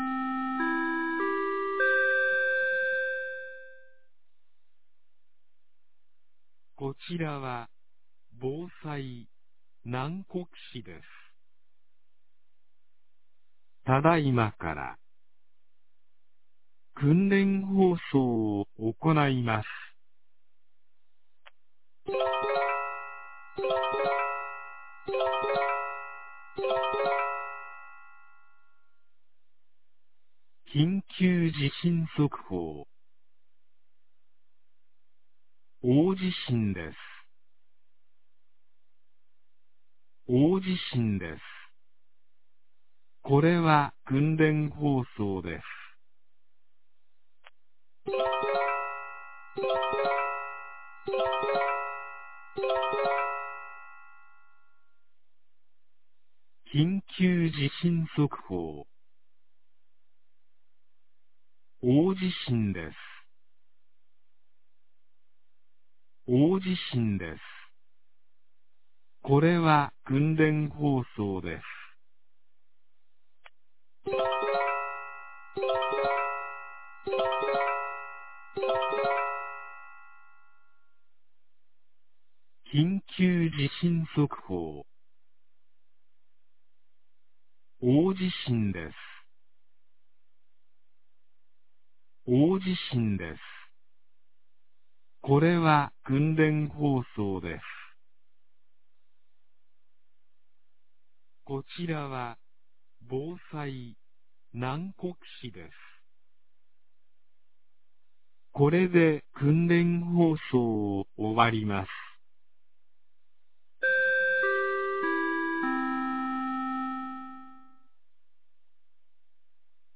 2022年11月02日 10時01分に、南国市より放送がありました。